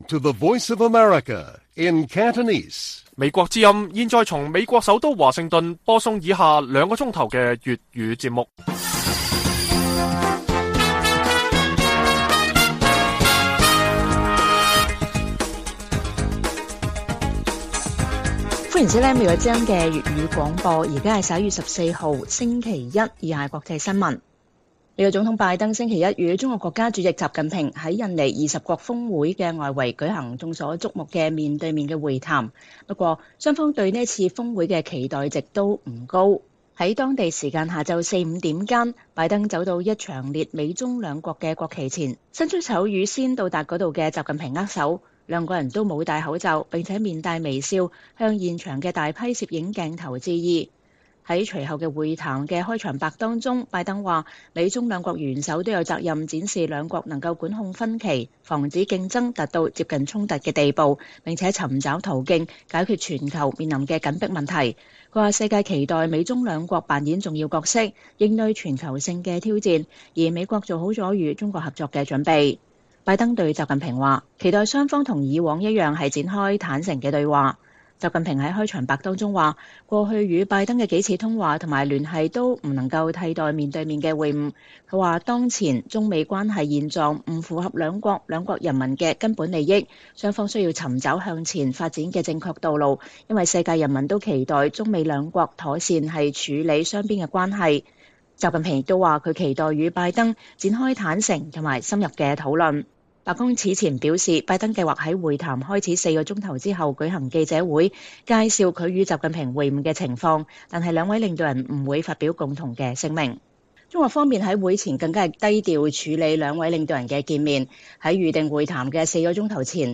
粵語新聞 晚上9-10點: 拜登面晤習近平